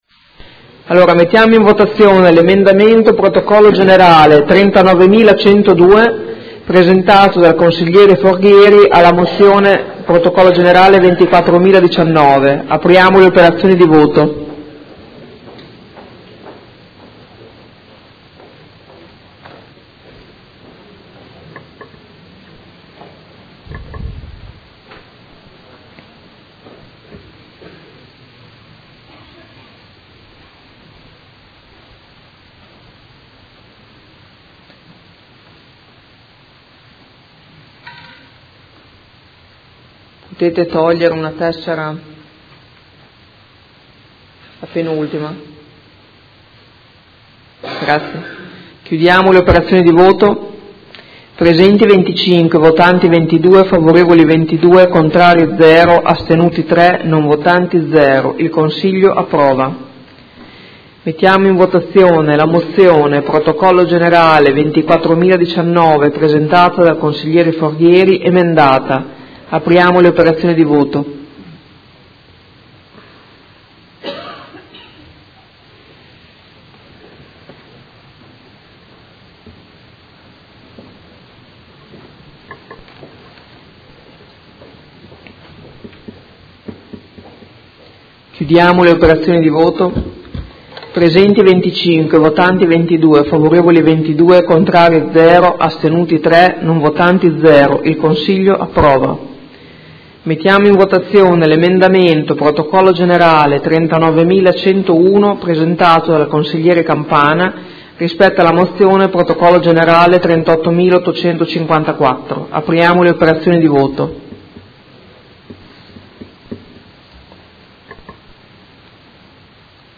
Presidente — Sito Audio Consiglio Comunale
Presidente
Seduta del 15/03/2018.